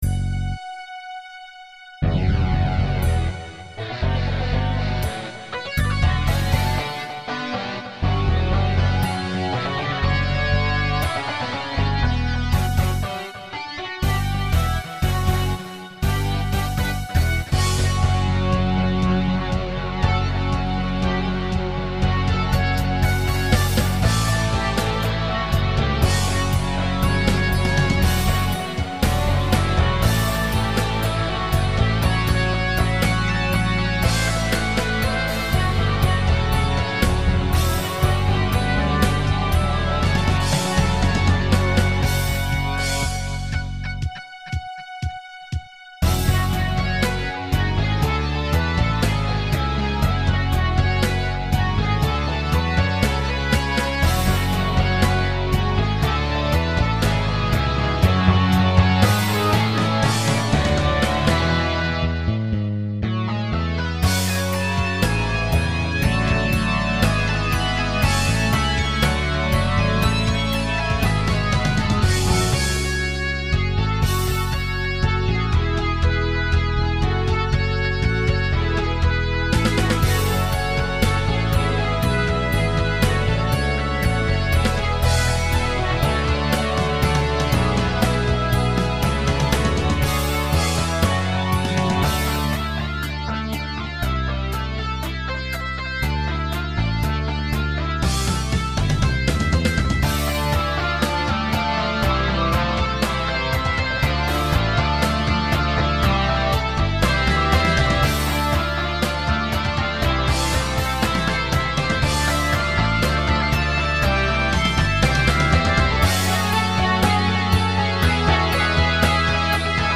An old prog rock tune of mine that is good in mp3. Tribute to the U.S. Air Force.